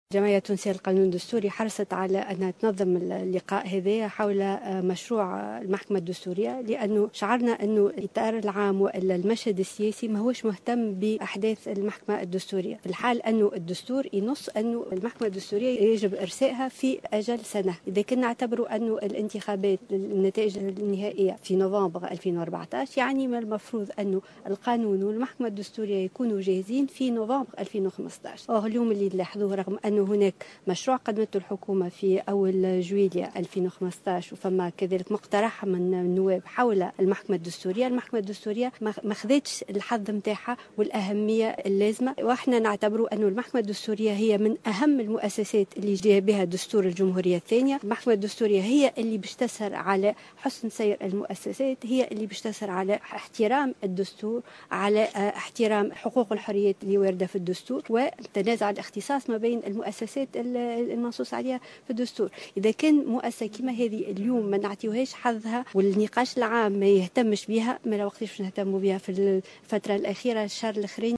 تصريح
خلال مائدة مستديرة حول مشروع قانون المحكمة الدستورية